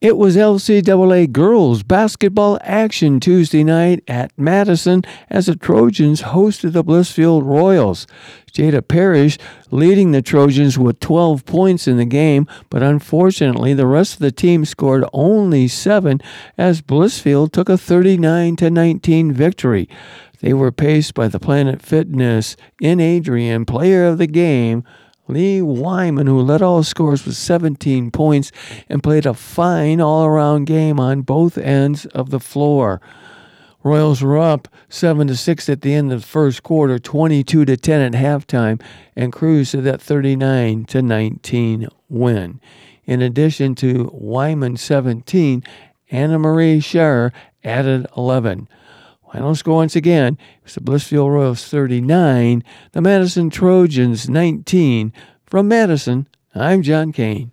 called the game on 96.5 The Cave and Lenawee TV…